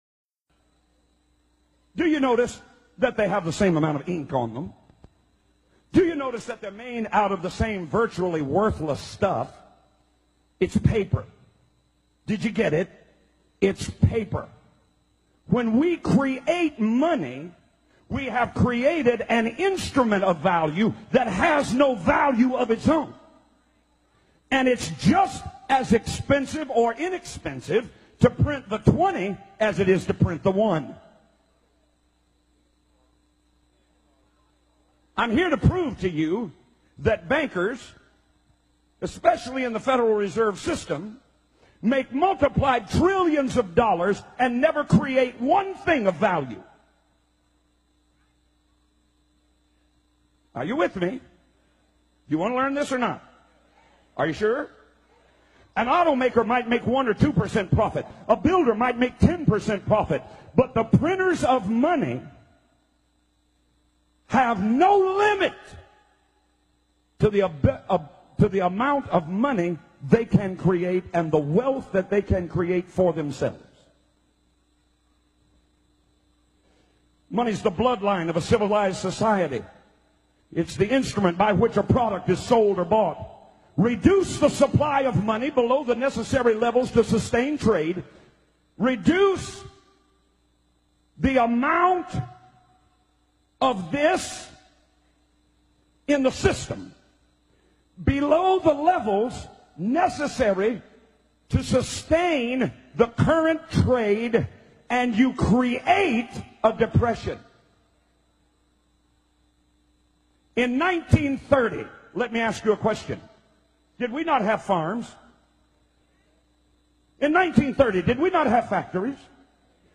The preacher explains that bankers are fraudsters and money is worthless paper